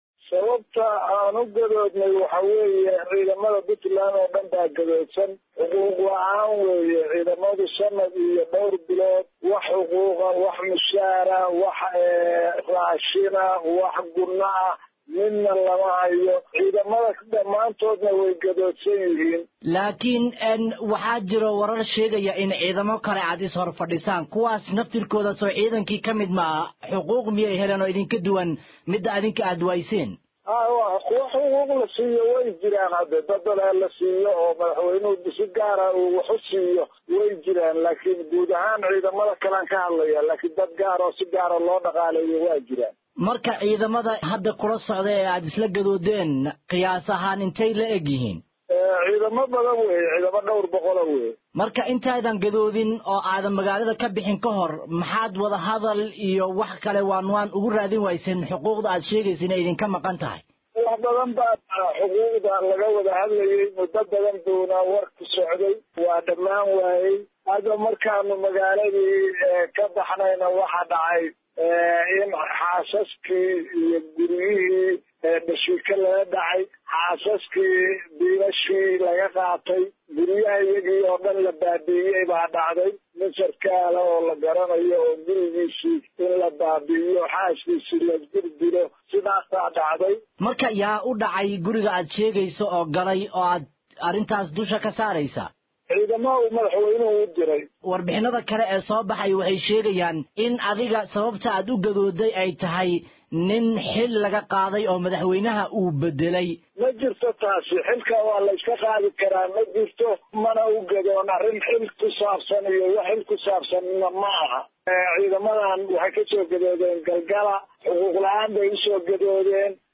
Taliye ku xigeenka ciidanka booliiska Puntland, Muxyadiin Axmed Muuse oo Ciidankan hoggaaminaya ayaa wareysi uu siiyay BBC-da ku sheegay in ciidamadiisa sababta ay uga gadoodeen maamulka Puntland ay tahay xuquuq la’aan.